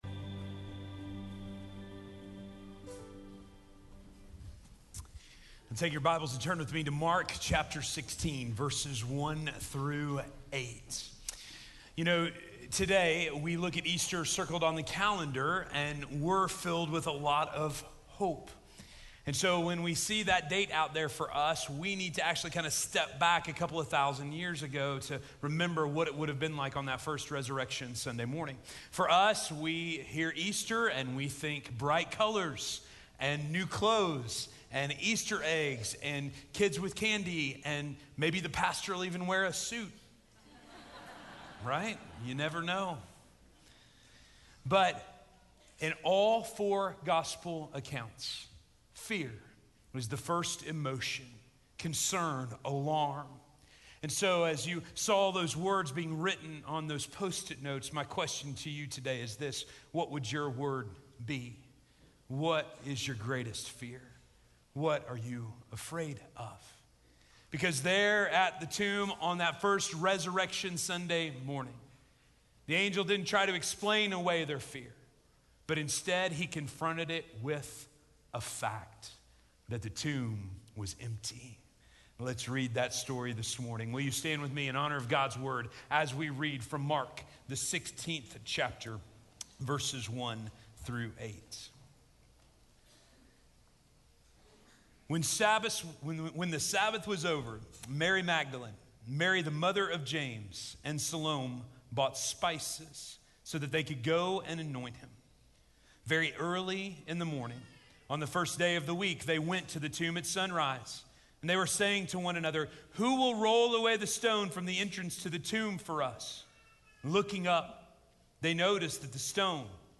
Resurrection - Sermon - Station Hill